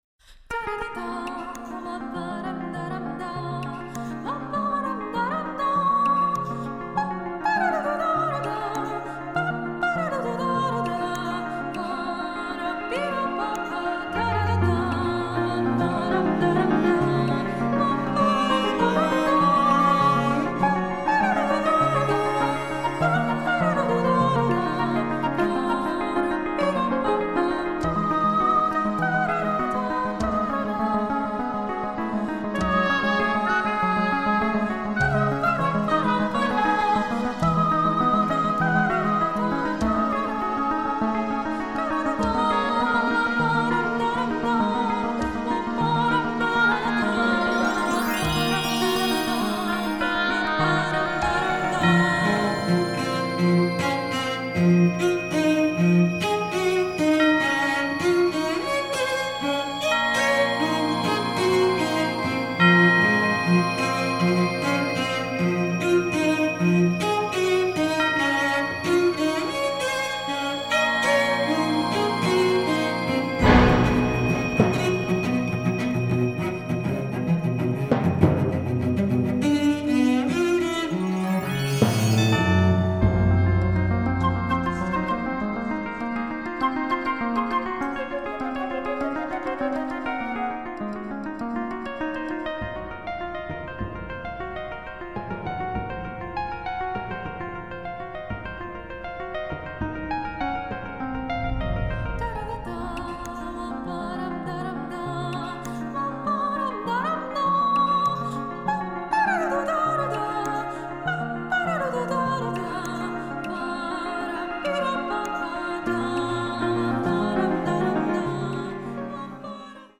"неоклассика" - от академизма до прогрессивного фолка.